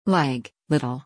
LEG/leɡ/, LITTLE /ˈlɪt̬.əl/
leg.mp3